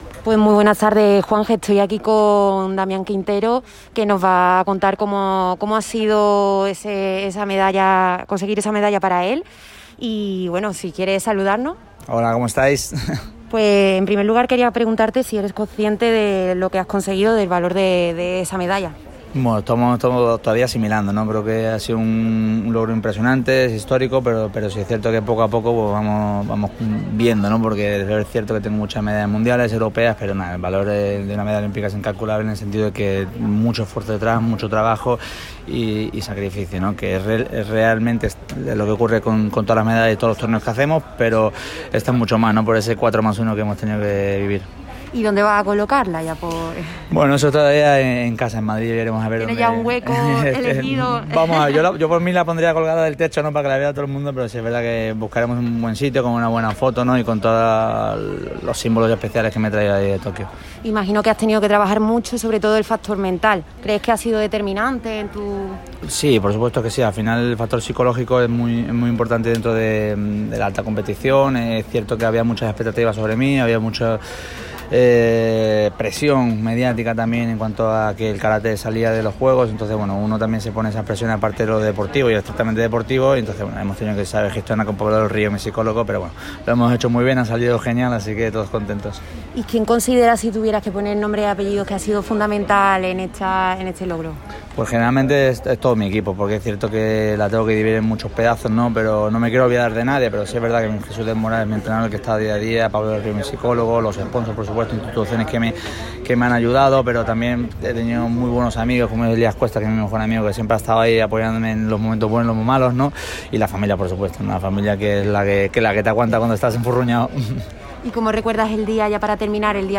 El malagueño Damián Quintero habló de su medalla de plata de Karate en los JJOO de Tokio. Lo hizo emocionado y todavía sin poder creérselo, así lo dijo ante los medios de comunicación.
El malagueño también atendió expresamente al micrófono rojo de Radio Marca Málaga para desvelar el valor que tiene la medalla para él.